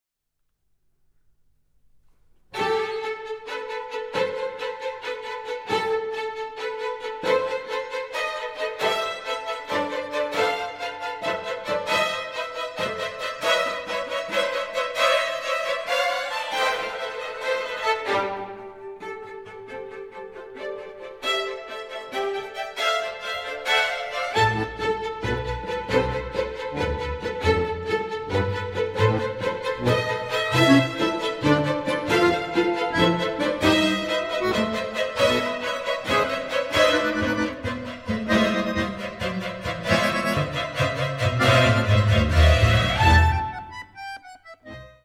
Andante cantabile (6:39)